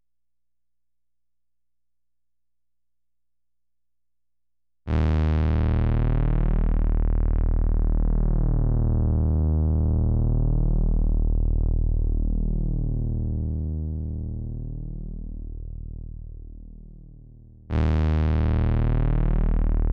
Bass Pedal_02.wav